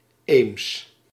The Ems (German: Ems [ɛms] ; Dutch: Eems [eːms]
Nl-Eems.ogg.mp3